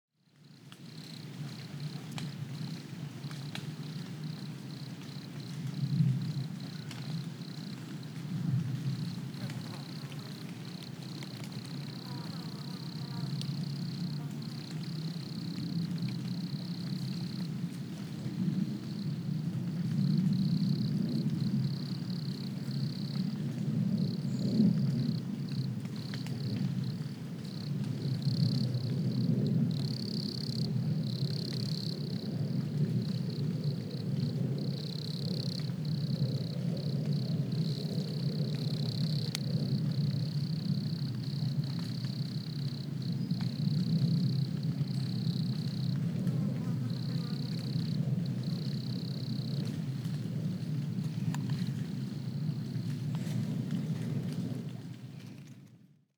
Beim Neue-Musik-Festival "Der Sommer in Stuttgart" im Wald aufgenommene Klänge, zum Anhören bitte anklicken:
Grillen und Flugzeug in der Mähderklinge,
GrillenuFlugzeugeMaeherklingenteich.mp3